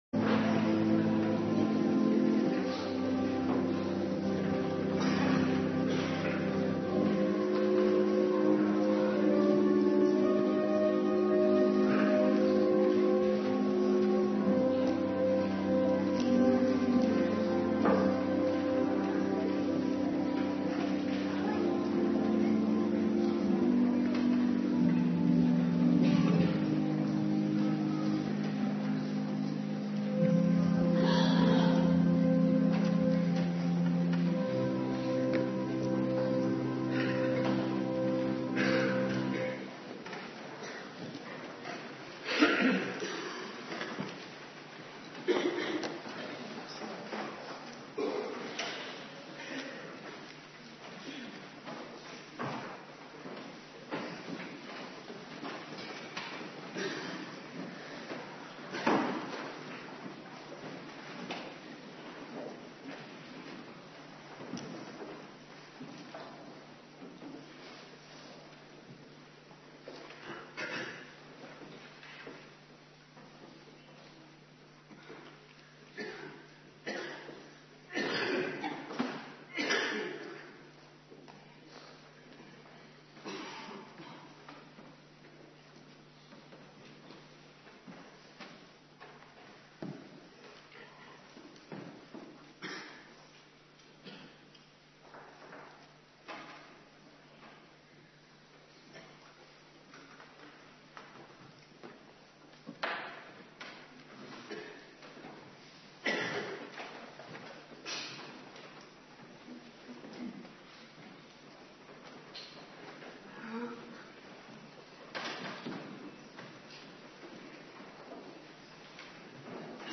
Morgendienst
Locatie: Hervormde Gemeente Waarder